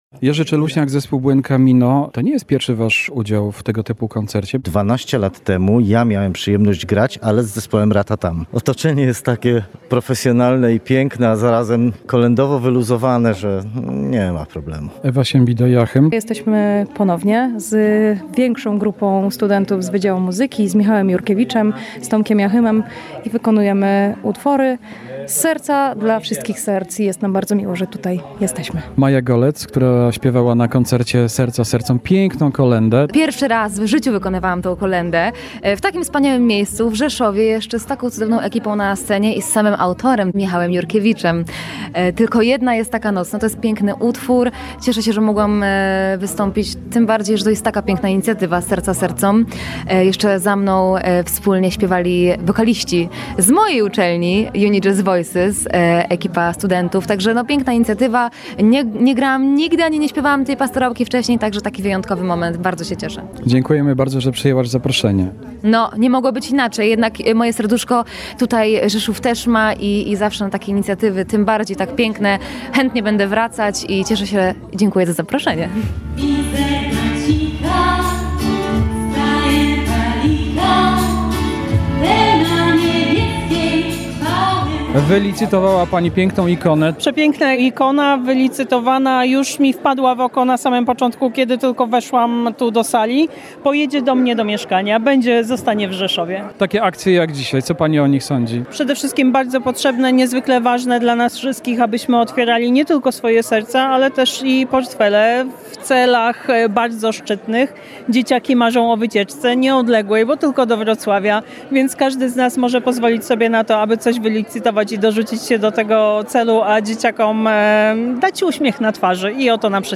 Koncert „Serca Sercom” w Rzeszowie. 24 tys. zł dla Domu Dziecka w Strzyżowie [ZDJĘCIA] • Relacje reporterskie • Polskie Radio Rzeszów
Relacje reporterskie • Podczas tegorocznego koncertu charytatywnego „Serca Sercom”, który odbył się w Auli Politechniki Rzeszowskiej, zebrano 24 tysiące złotych.